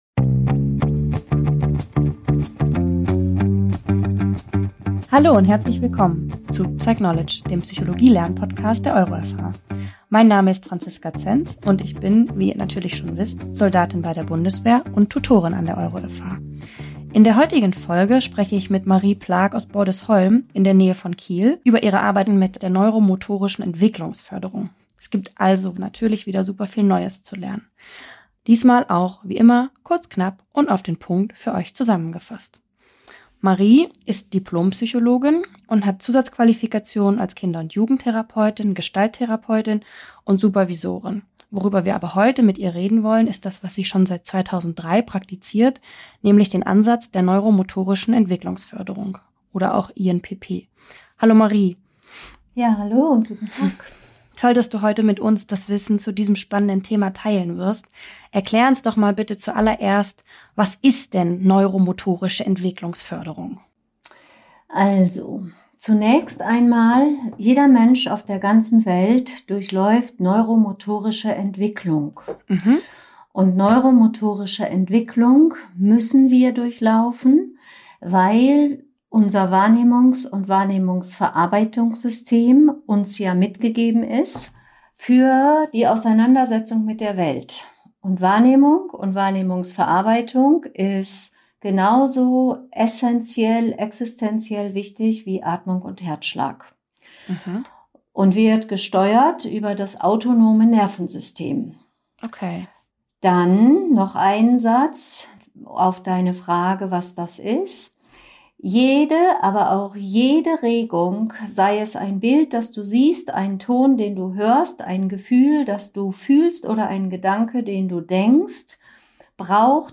In dieser Folge sprechen wir mit der Psychologin und Psychotherapeutin